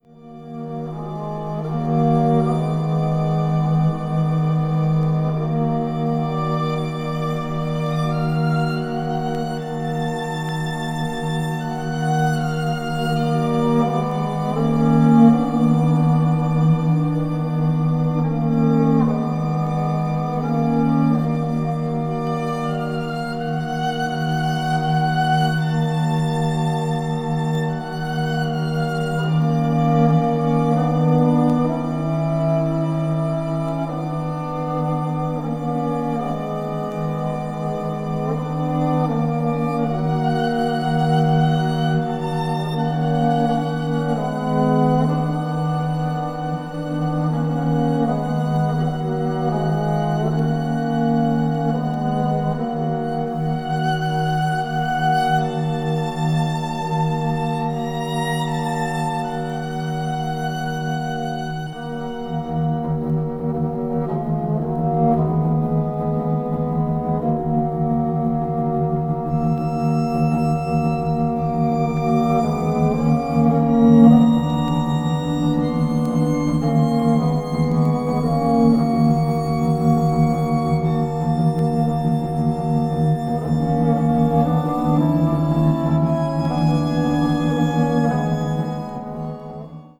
media : EX/EX(わずかにチリノイズが入る箇所あり)
コンサート・ホール独特のリヴァーブ効果によって弦楽器の豊かな響きを体験できる秀逸な録音です。
20th century   chamber music   contemporary   post modern